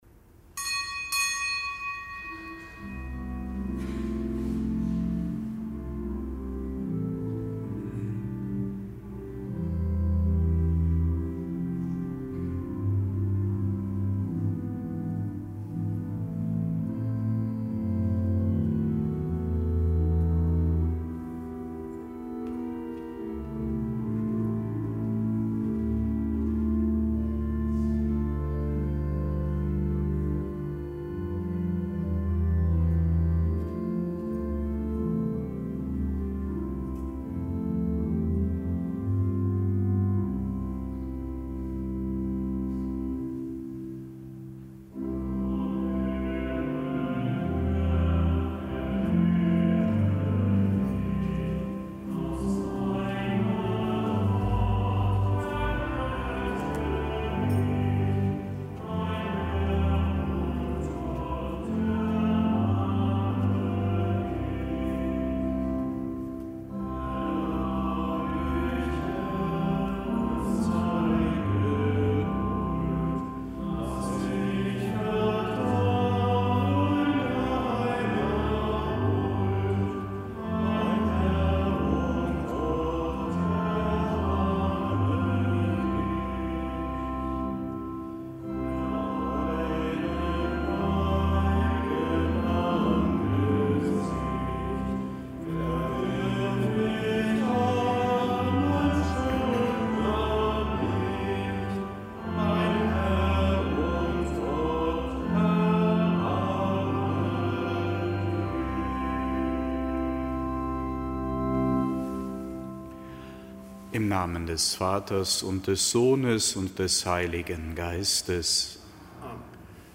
Kapitelsmesse am Donnerstag der zweiten Fastenwoche
Kapitelsmesse aus dem Kölner Dom am Donnerstag der zweiten Fastenwoche.